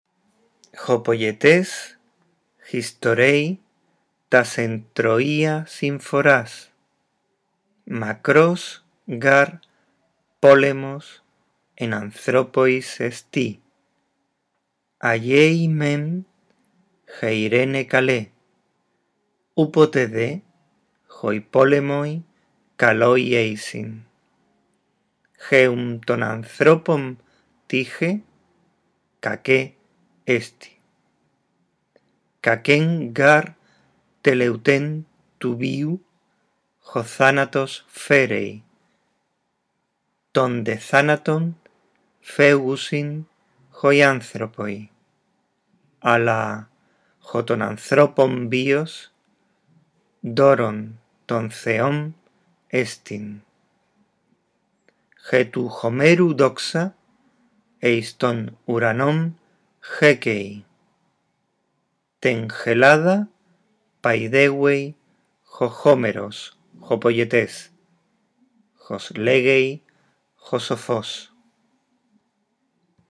Lee despacio y en voz alta el texto griego; procura pronunciar unidos los sintagmas, los artículos te ayudarán.